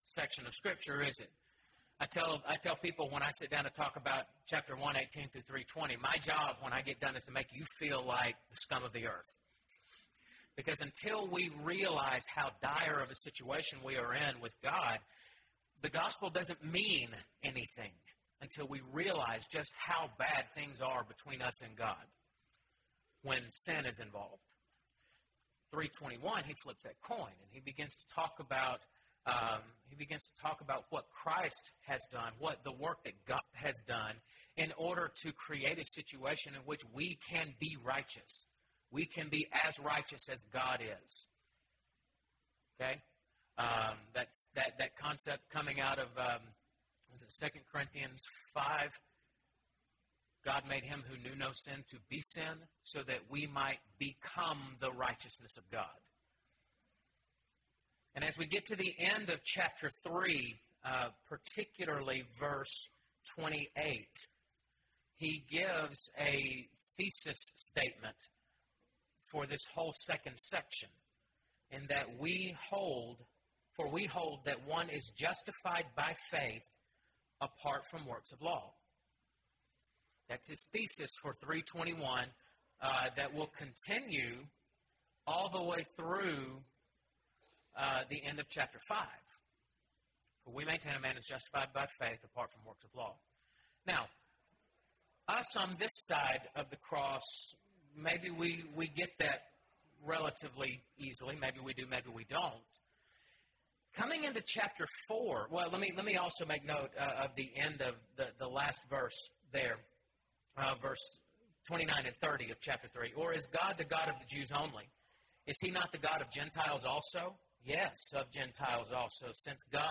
Romans – (Guest Speaker) – Bible Lesson Recording